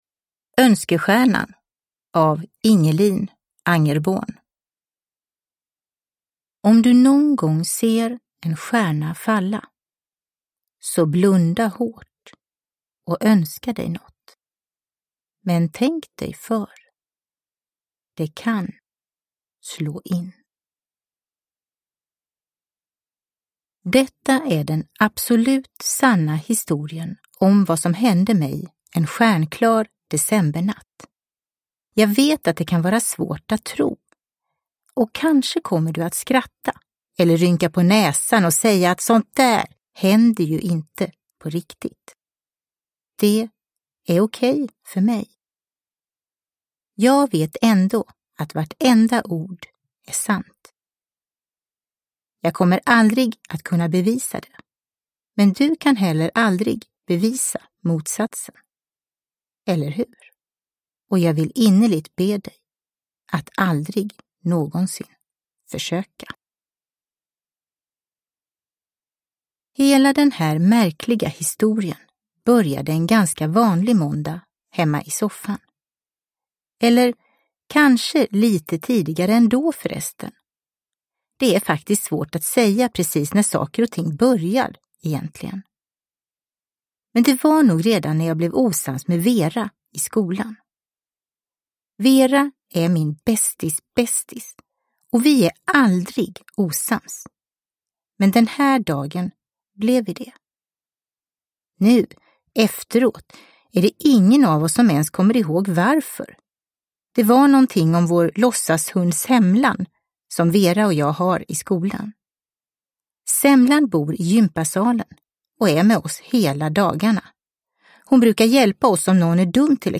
Önskestjärnan – Ljudbok – Laddas ner